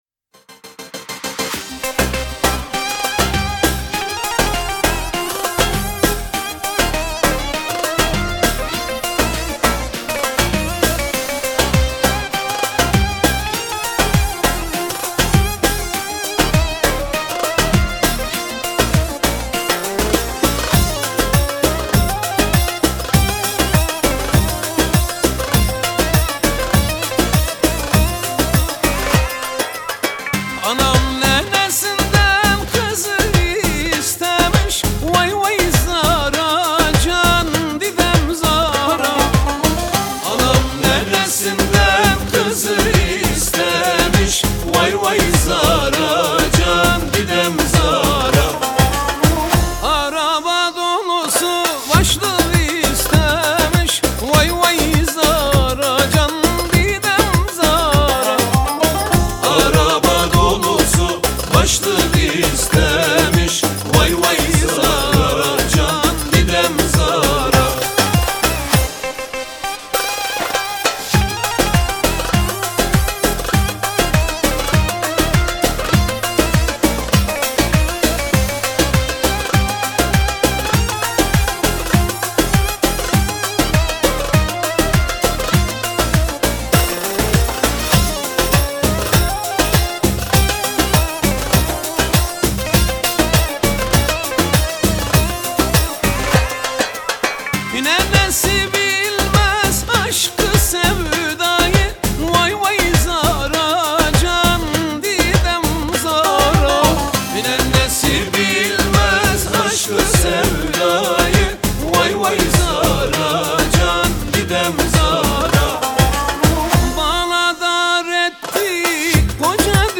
Listen And Download Turkish Music